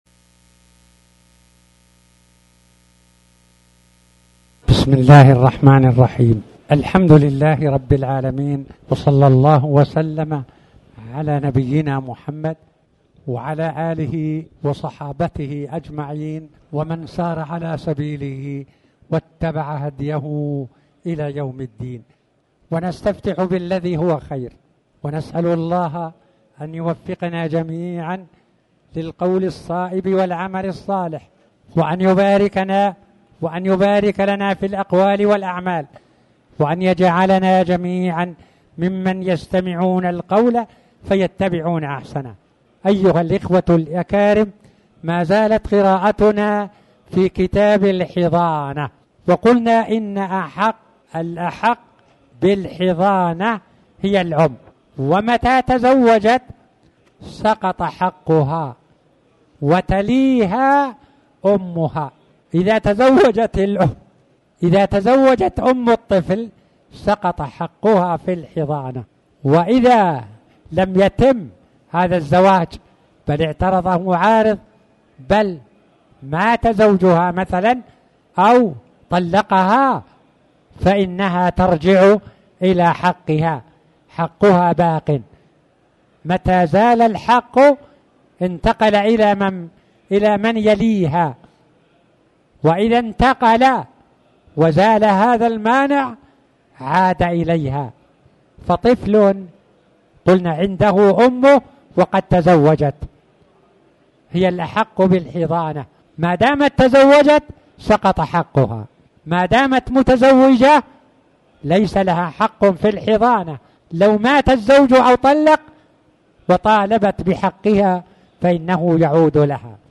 تاريخ النشر ١ رجب ١٤٣٩ هـ المكان: المسجد الحرام الشيخ